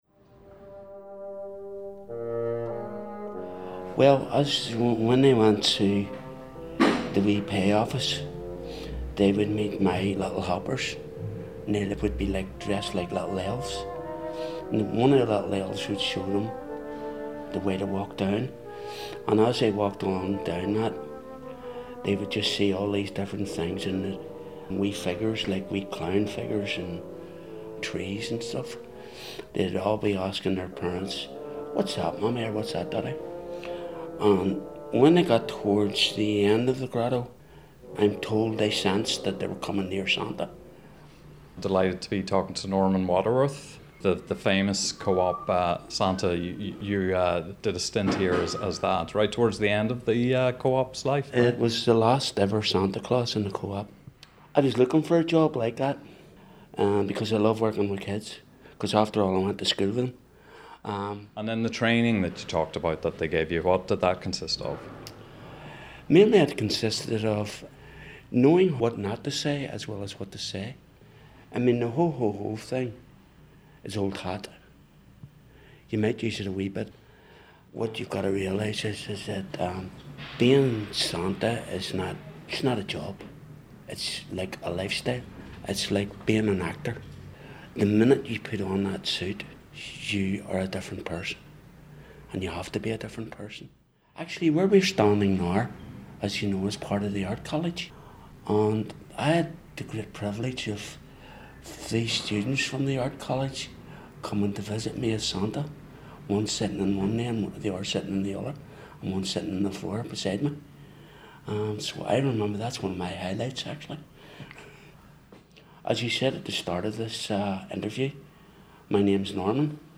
An installation based around an extended audio piece that takes the form of a radio documentary exploring the Orpheus Building in Belfast: former home of the Co-Op department store, Belfast's celebrated Orpheus ballroom, and latterly the University of Ulster's Fine Art department.
Temporal Rendering: The Orpheus Building is drawn from of a series of recordings made at the building as its demolition loomed in 2015; interviews were conducted with a range of people who had used the building over the years, including former Co-Op shoppers and staff, showband musicians, architectural historians, and art students.